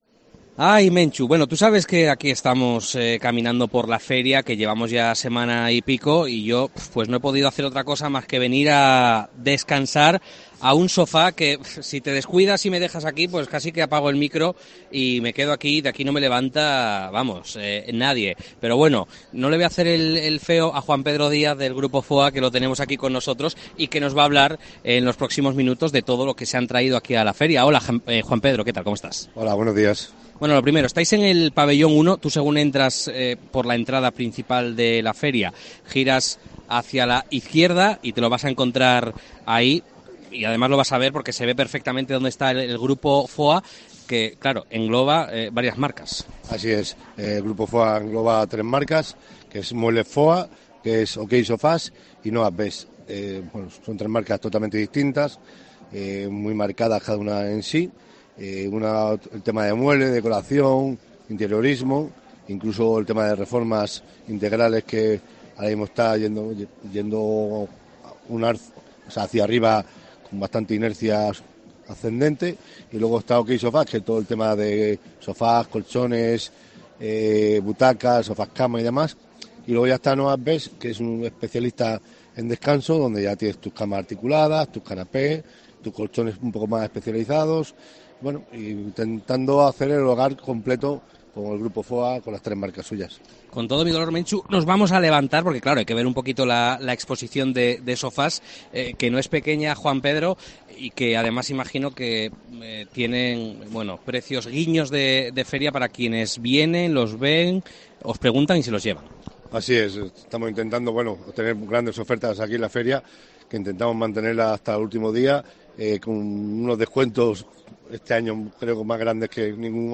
FIDMA 2023: entrevista